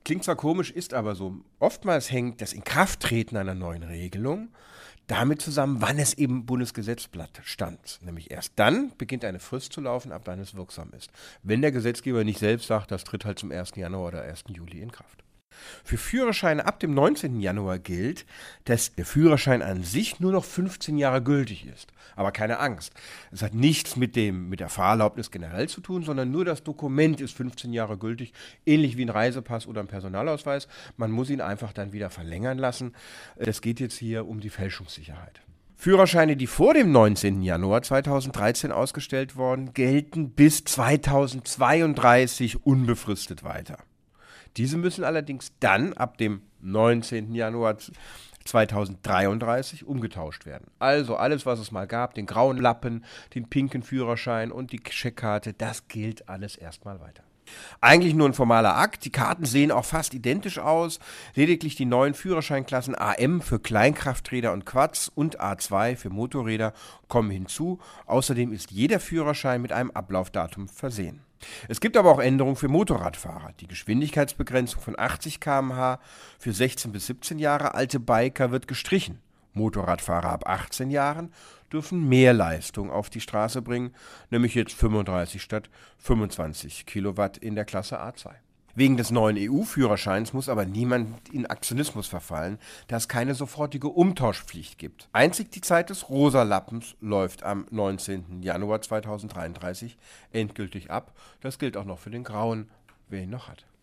O-Töne / Radiobeiträge, , , ,